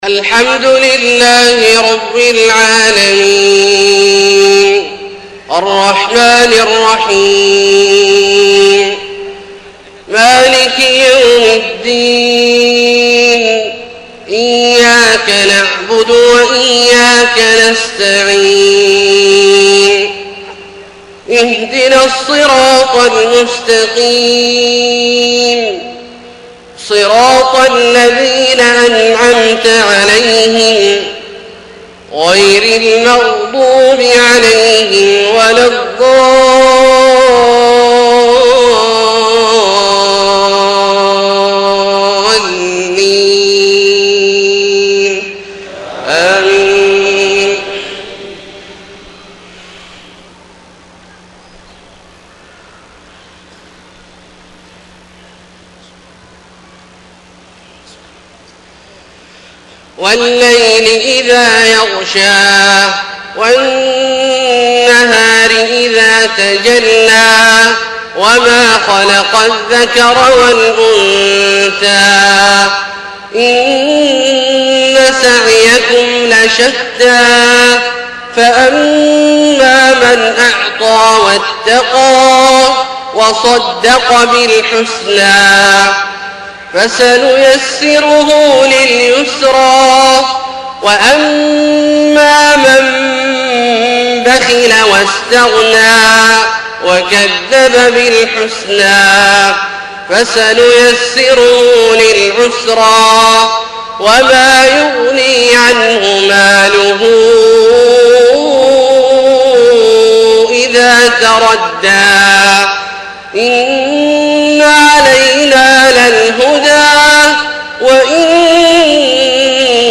صلاة العشاء 5-8-1431 سورتي الليل و الضحى > ١٤٣١ هـ > الفروض - تلاوات عبدالله الجهني